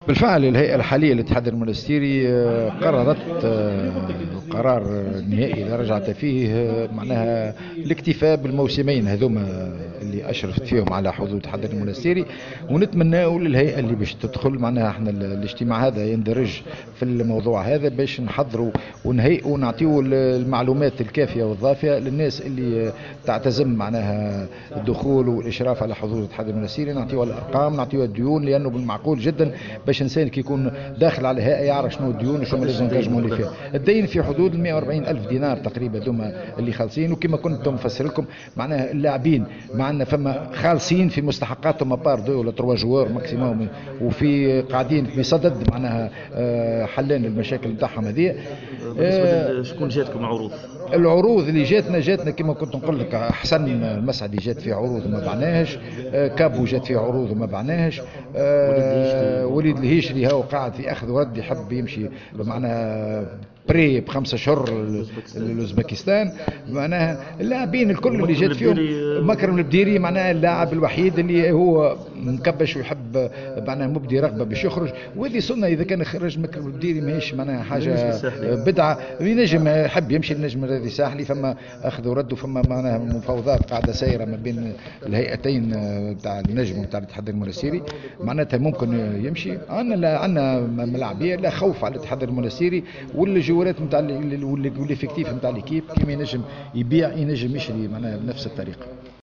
عقدت الهيئة المديرة للإتحاد المنستيري ندوة صحفية اليوم الثلاثاء 19 جوان 2018 بمقر الفريق لتسليط الأضواء حول الوضعية الإدارية والمالية للنادي قبل الجلسة العامة العادية المقررة عقدها يوم 3 جويلية ثم الجلسة العامة الانتخابية يوم 10جويلية 2018 .